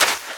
STEPS Sand, Run 07.wav